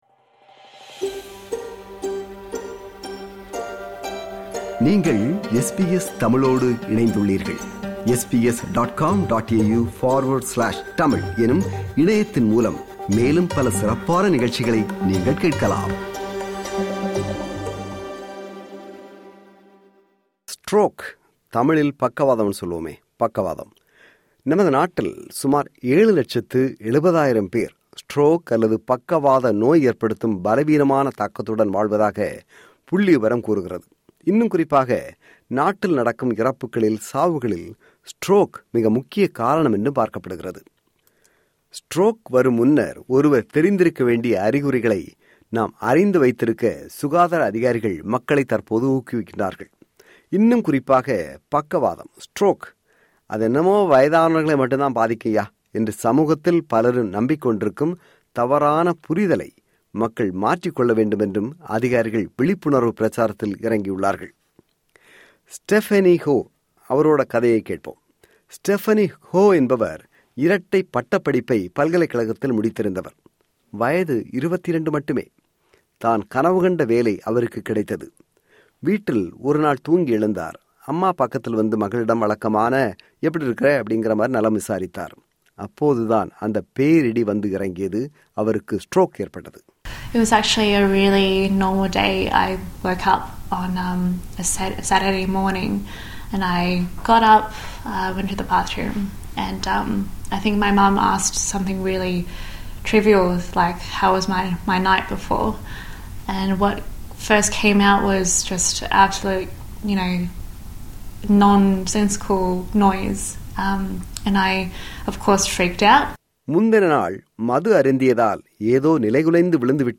பக்கவாதம் என்பது வயதானவர்களுக்கு வரும் ஆபத்தான நோய் என்று நம்மில் பலரும் நம்பிக்கொண்டிருக்கலாம். ஆனால் அது உண்மை இல்லை என்று விளக்கும் விவரணம்.